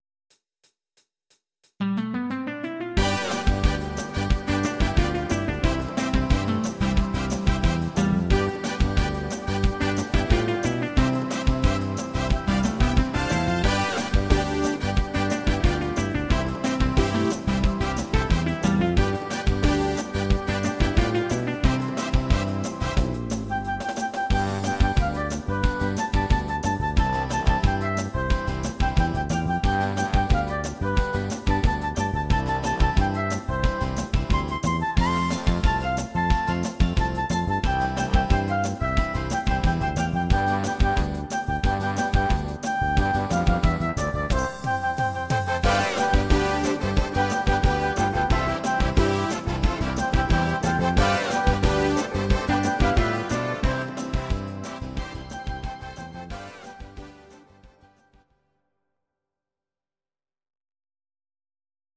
Audio Recordings based on Midi-files
Pop, German, Duets